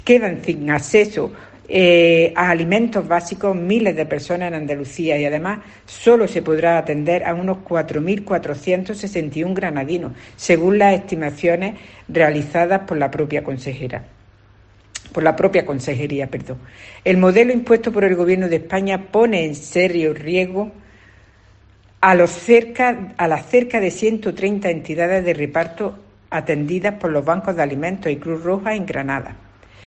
Matilde Ortiz, delegada de Inclusión social